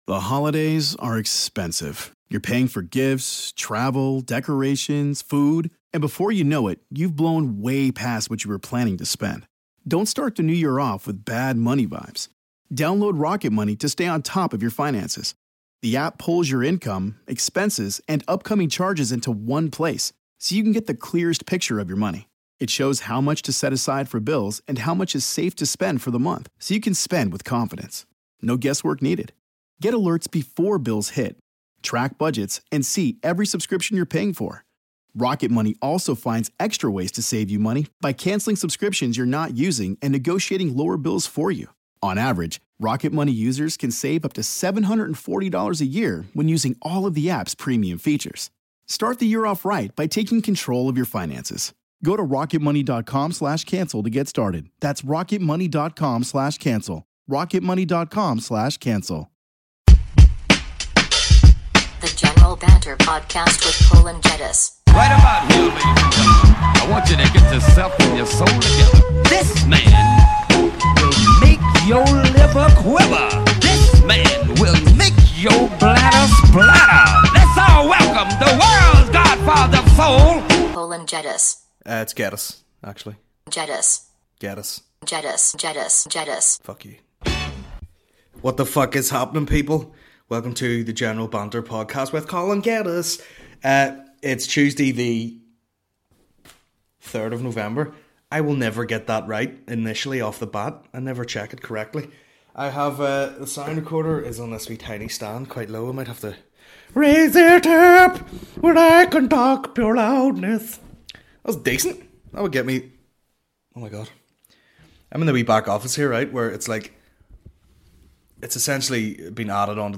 The General Banter Podcast is a Comedy podcast